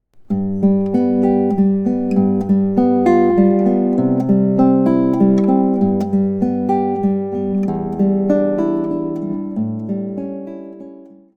Here's what the P-i-m-a-m-i arpeggio sounds like when applied to the first phrase of Amazing Grace: